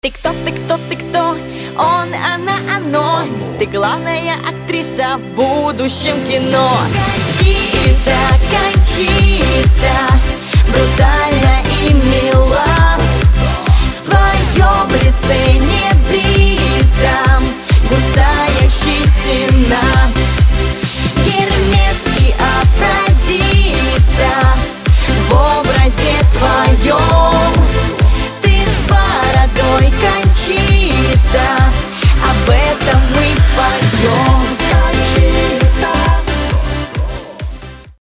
• Качество: 256, Stereo
поп